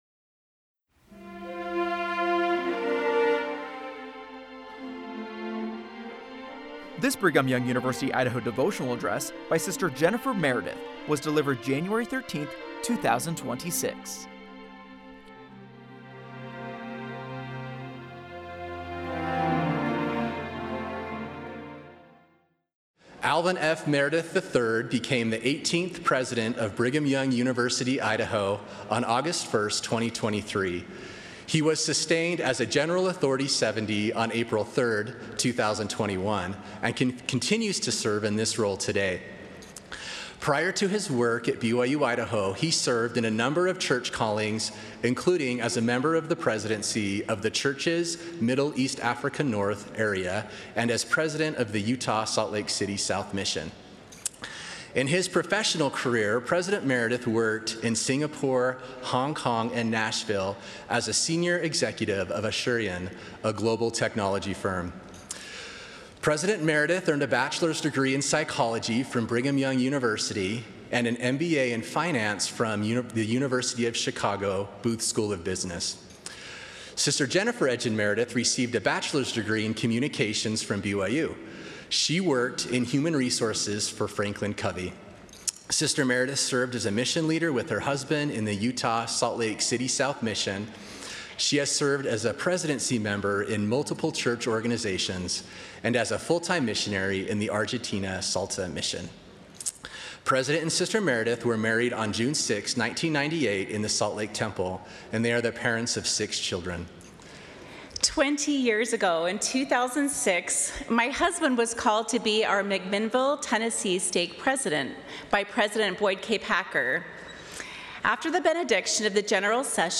Devotionals and Speeches